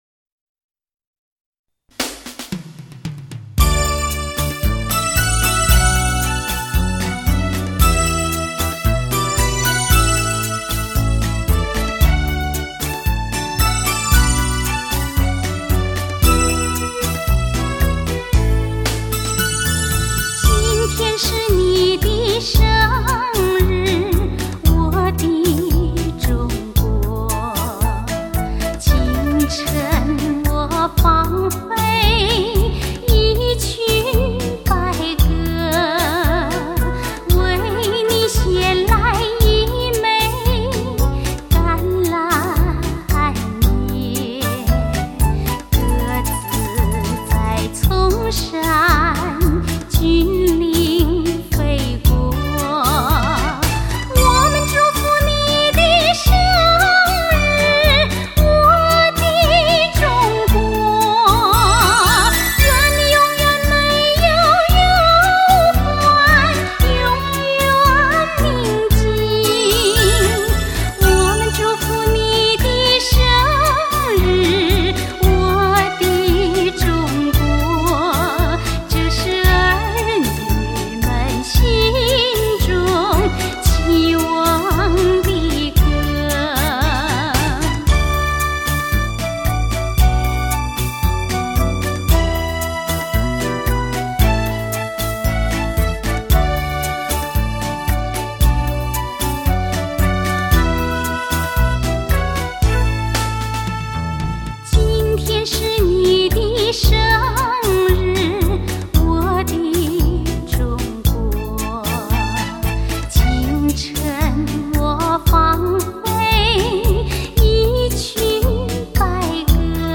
悠扬缠绵旋律优美的民韵脍炙人口婉转动人的民歌